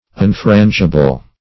Search Result for " unfrangible" : The Collaborative International Dictionary of English v.0.48: Unfrangible \Un*fran"gi*ble\, a. Infrangible.